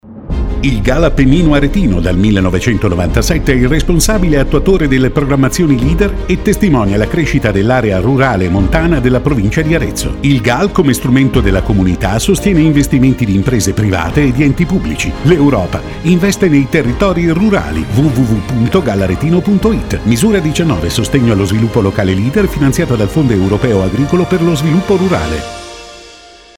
Radio Valtiberina spot n. 2